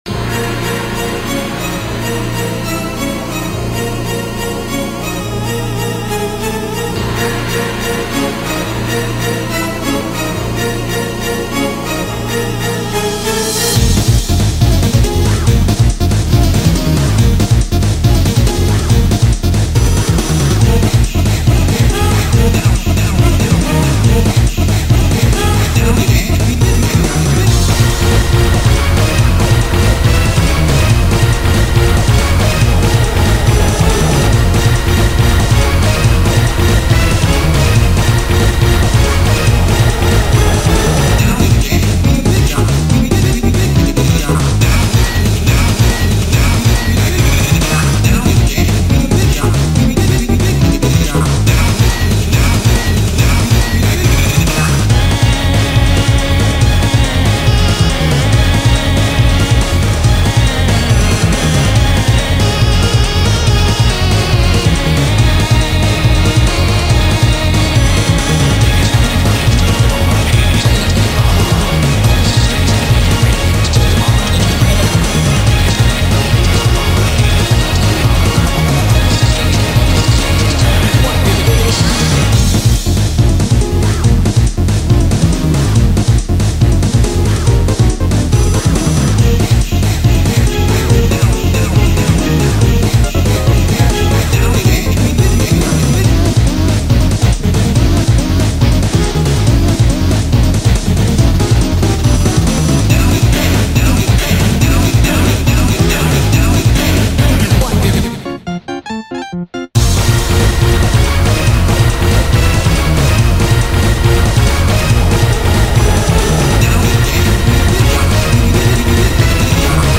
BPM70-280
Audio QualityPerfect (Low Quality)